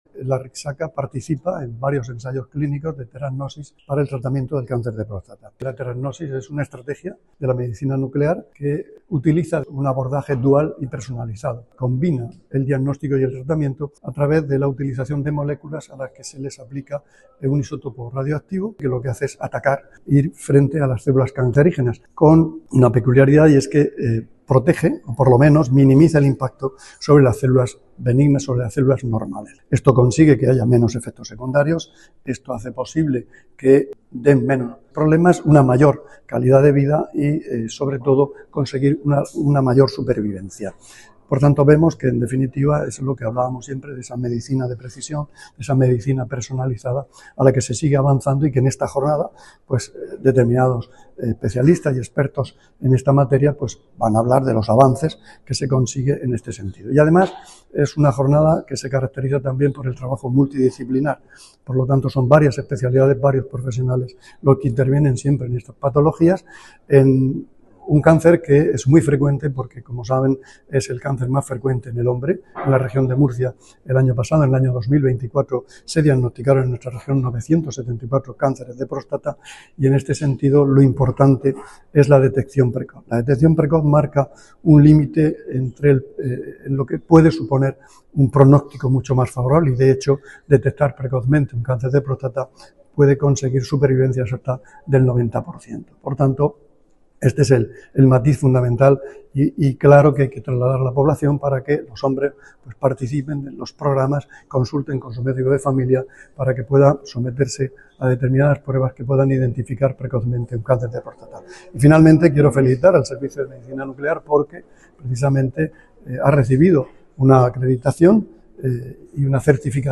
Sonido/ Declaraciones del consejero de Salud sobre la teragnosis aplicada al tratamiento del cáncer de próstata.
El consejero de Salud inauguró hoy una jornada de formación en teragnosis en la Arrixaca.